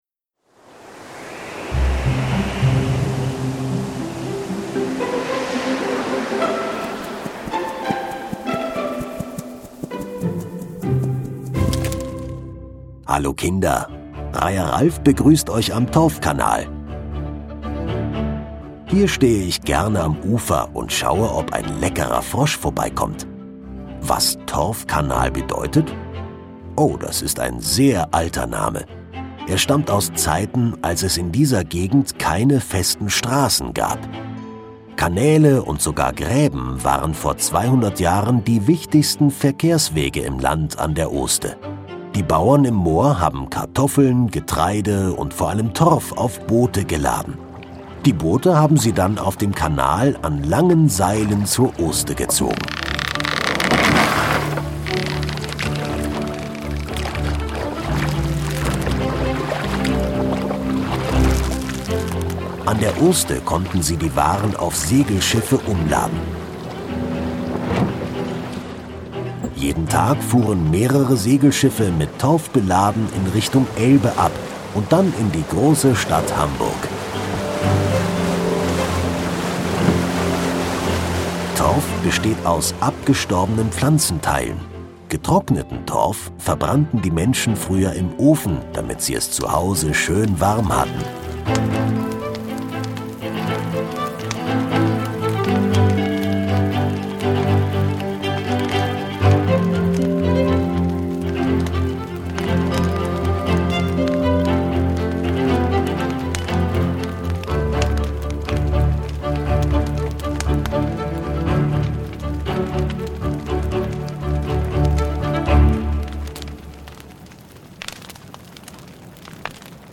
Torfkanal - Kinder-Audio-Guide Oste-Natur-Navi